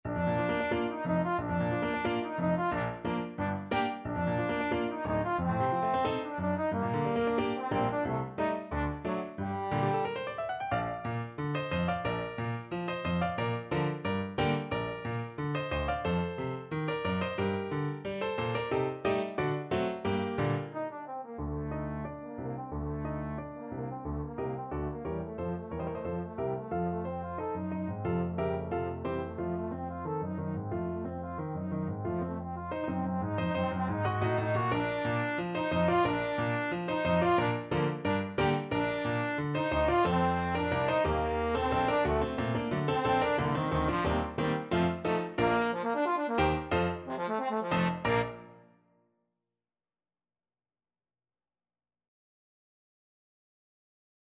Classical Brahms, Johannes Violin Concerto, Op.77, Third Movement (Main Theme) Trombone version
Bb major (Sounding Pitch) (View more Bb major Music for Trombone )
2/4 (View more 2/4 Music)
~ = 100 Allegro giocoso, ma non troppo vivace =90 (View more music marked Allegro giocoso)
Trombone  (View more Intermediate Trombone Music)
Classical (View more Classical Trombone Music)
brahms_violin_concerto_3rd_TBNE.mp3